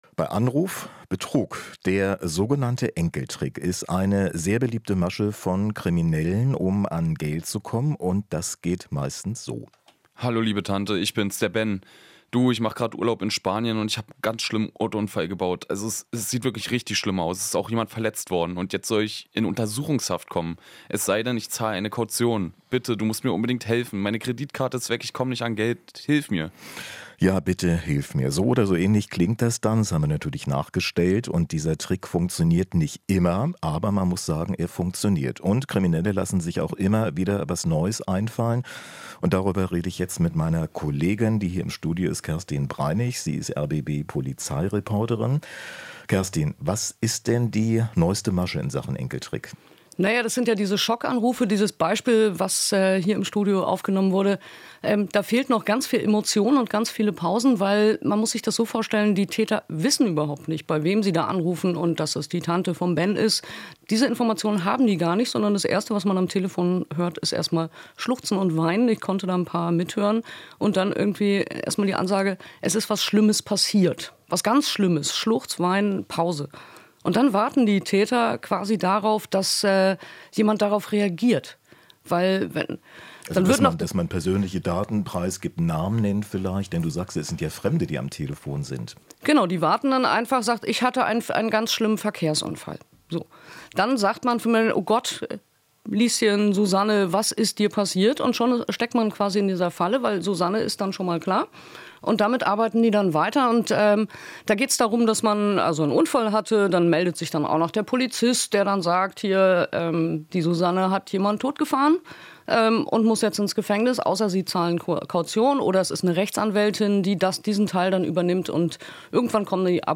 Interview - Bei Anruf Betrug: Neue Masche beim Enkeltrick